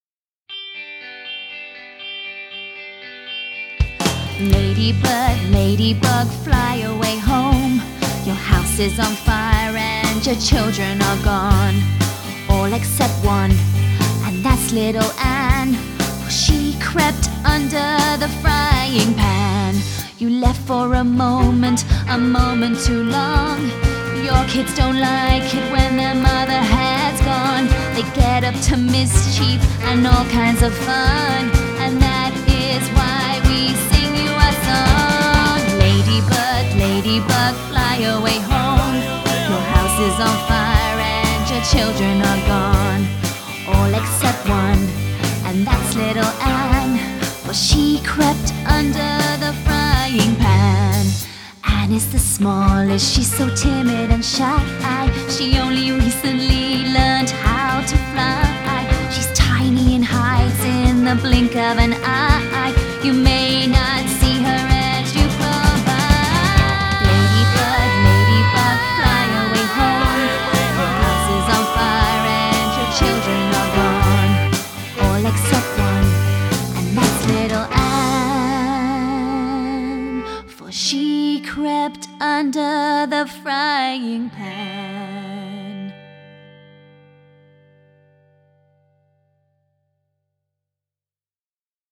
Spooky